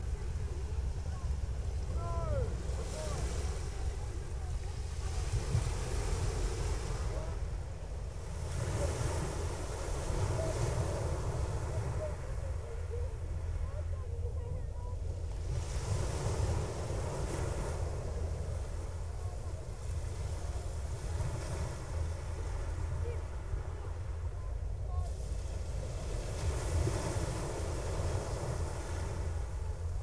英国布莱顿海滩上的夏日立体声录音。海浪拍打着海岸线。人们在背景中交谈。用Audio Technica AT835b amp; a Sony Minidisc录制。